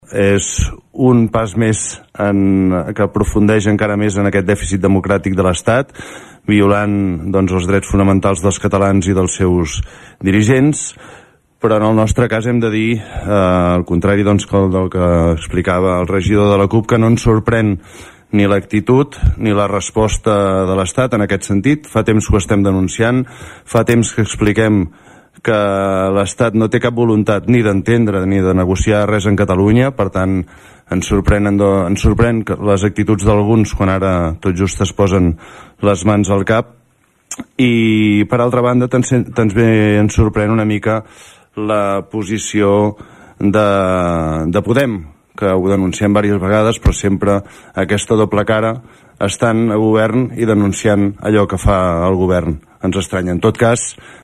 El ple de l‘Ajuntament de Tordera va aprovar una moció a favor de demanar al Congrés Espanyol la investigació del CatalanGate, un “espionatge il·legal de l’Estat contra els líders independentistes” que ha destapat el diari “New Yorker”.